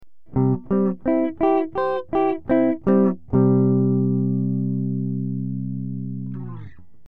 Harmony Using Chords
This is a trick to harmonize by holding a chord-plucking two strings-then mute the strings quickly.